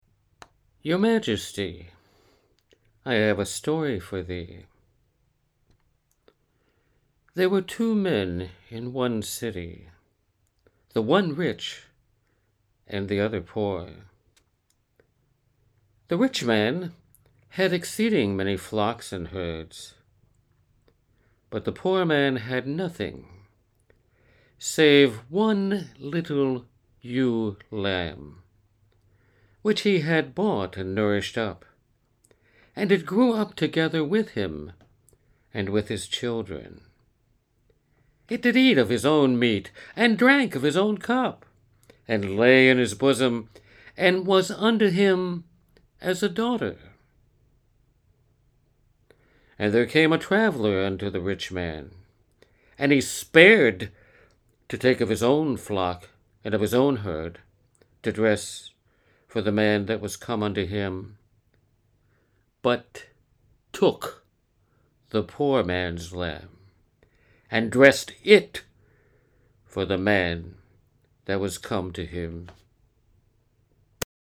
VOICE ACTING
Two recordings as the prophet Nathan in a biblical drama about King David.